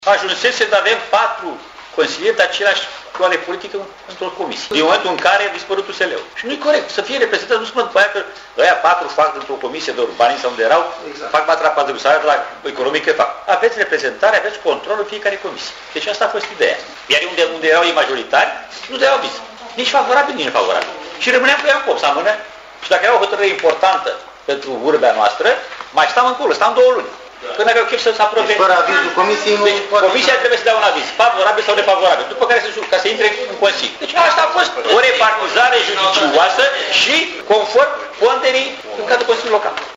Primarul Alexandru Stoica a replicat că schimbarea componenţei comisiilor de specialitate s-a impus pentru că s-au format noi majorităţi în urma cărora reprezentanţii dreptei blochează introducerea pe ordinea de zi a unor proiecte prin neeliberarea avizelor: